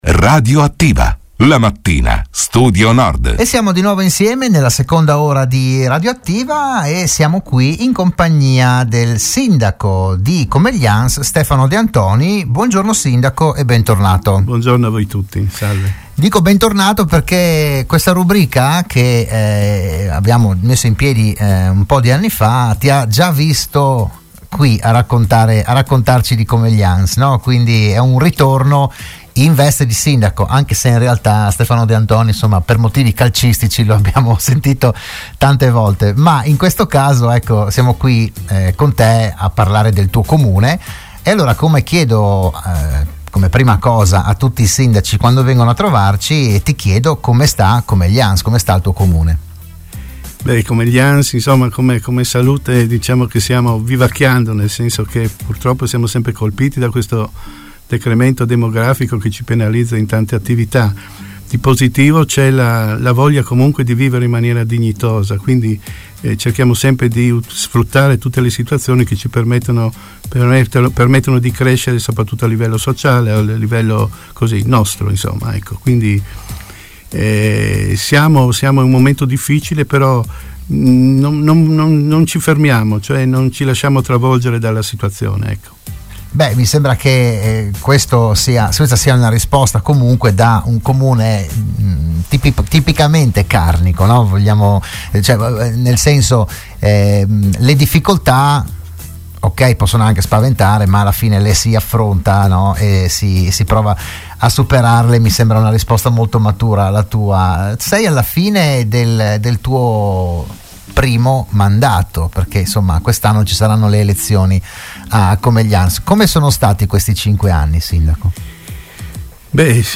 Ogni settimana il primo cittadino di un comune dell’Alto Friuli sarà ospite in studio, in diretta (anche video sulla pagina Facebook di RSN), per parlare del suo territorio, delle problematiche, delle iniziative, delle idee, eccetera.